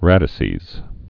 (rădĭ-sēz, rādĭ-)